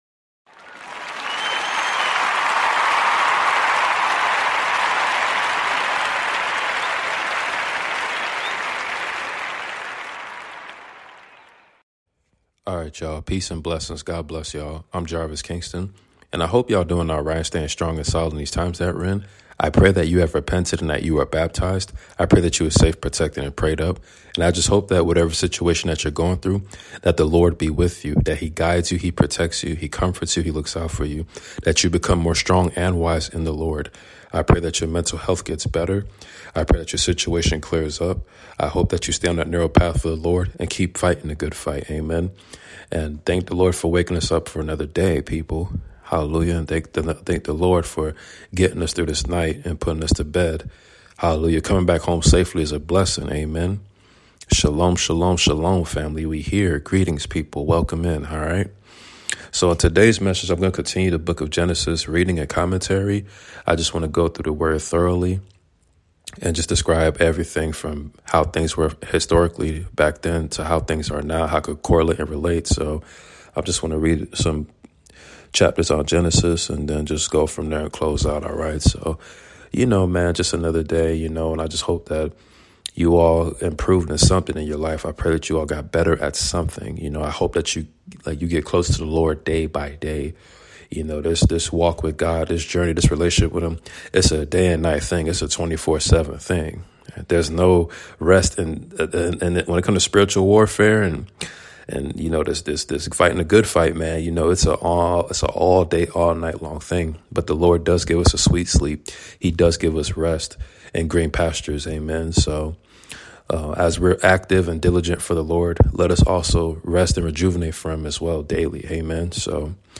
Noah | Shem | Lineage Of Israel | The Shemite Race | Shemetic People | Genesis Reading & Commentary Prayers